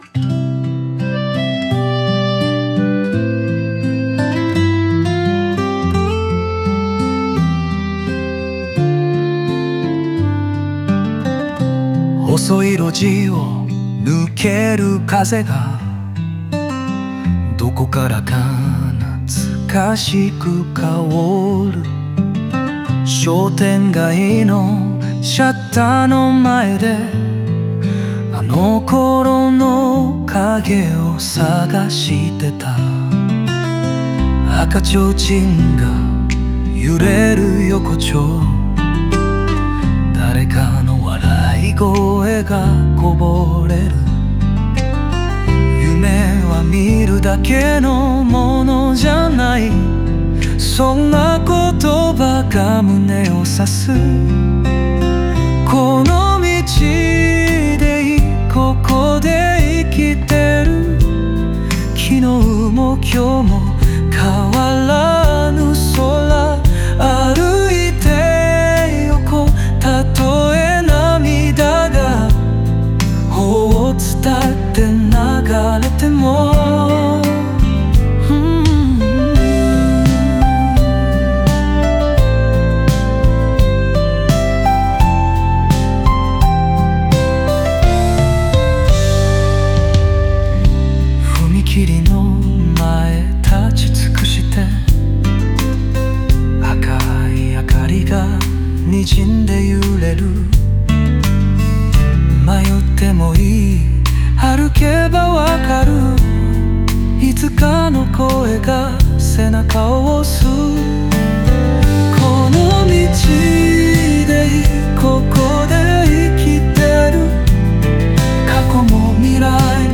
温かくも切ないフォークソング調で、聴く人の心に寄り添う楽曲になっています。